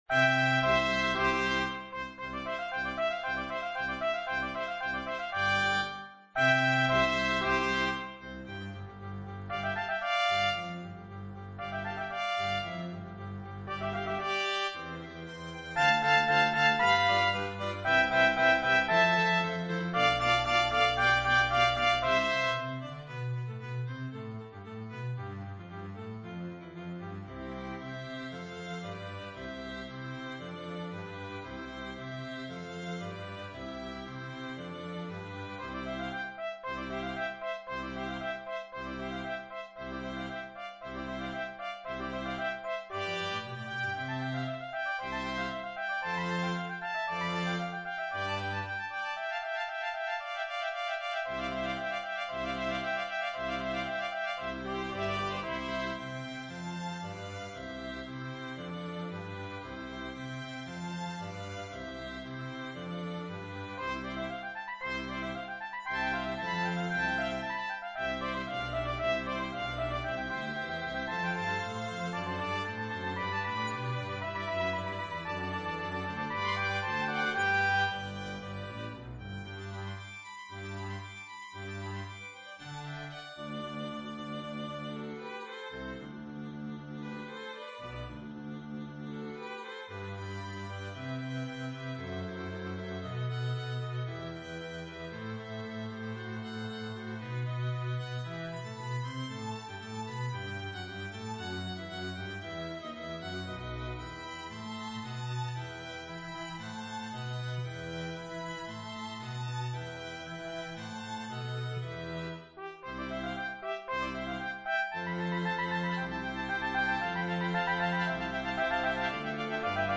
Voicing: Trumpet